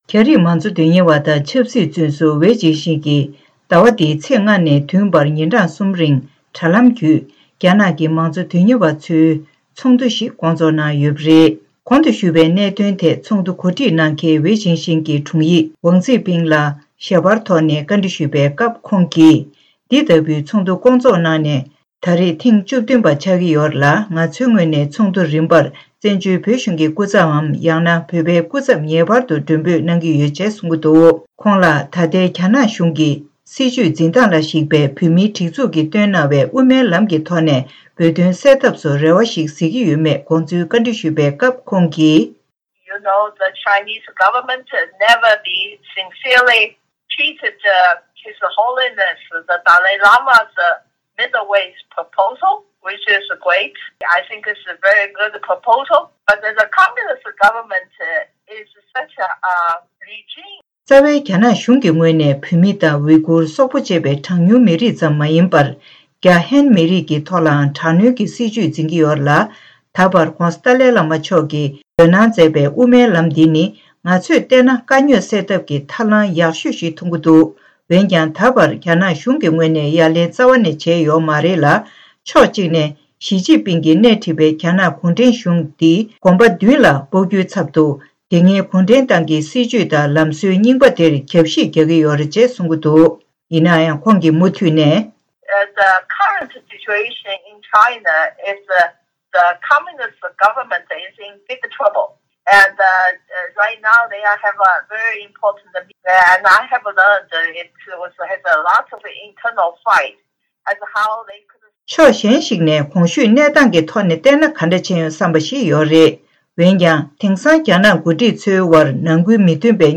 གསར་འགྱུར་དང་འབྲེལ་བའི་ལེ་ཚན་ནང་།